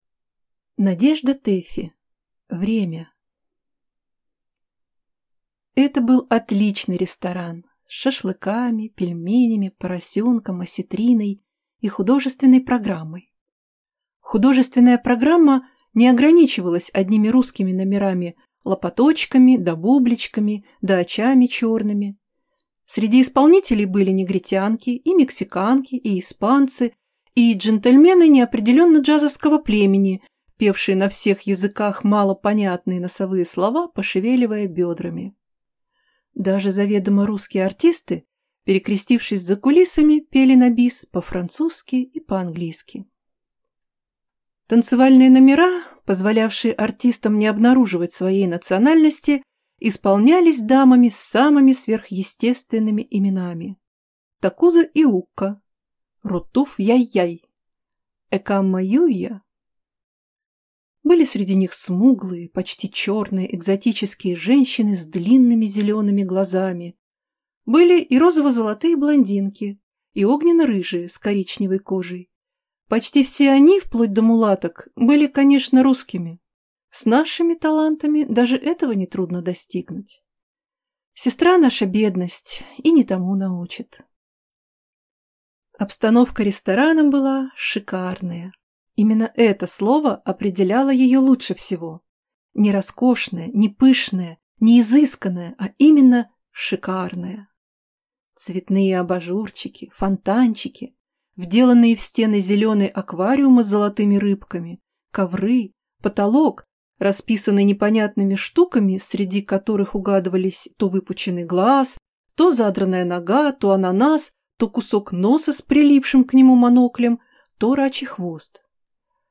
Аудиокнига Время | Библиотека аудиокниг